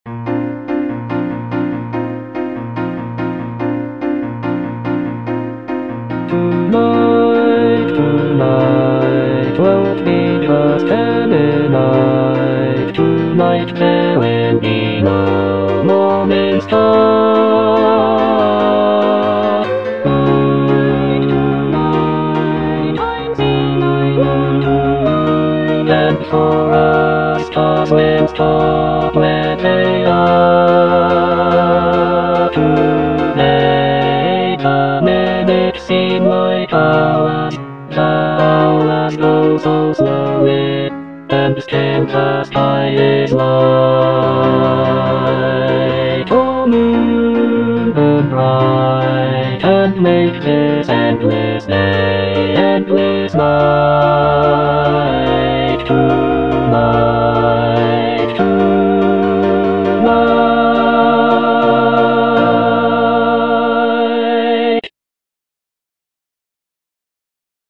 Bass (Emphasised voice and other voices) Ads stop
medley